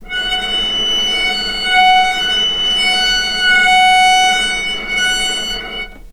vc_sp-F#5-mf.AIF